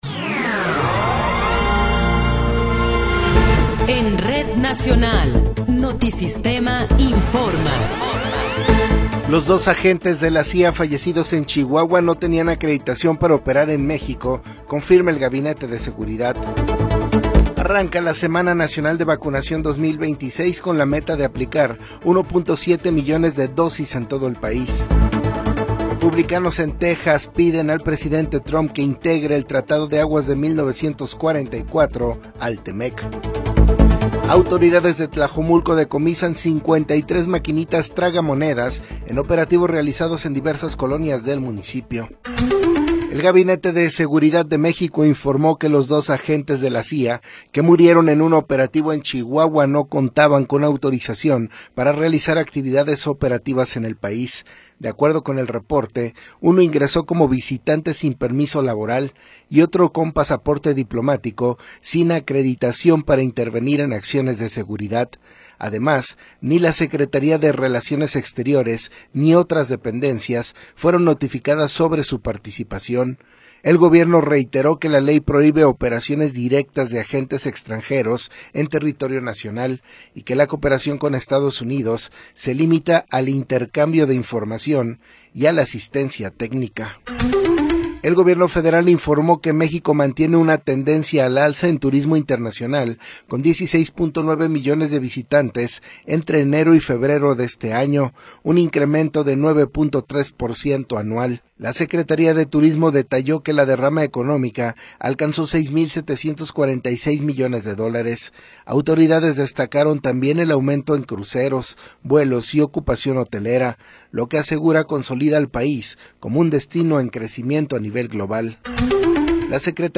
Noticiero 14 hrs. – 25 de Abril de 2026